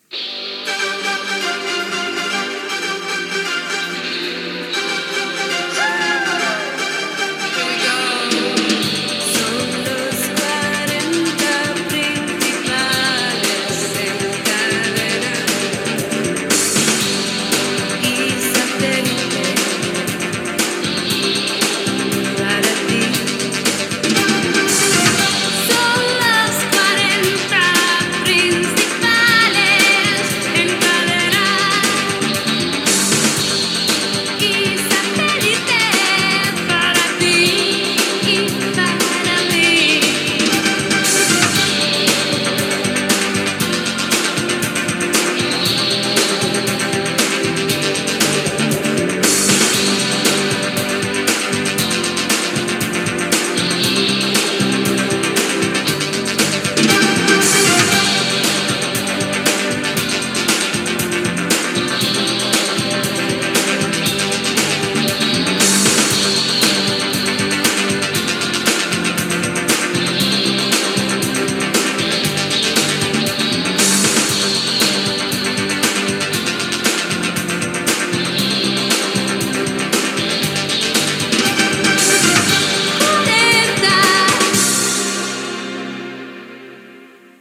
Cançó identificativa
"jingle"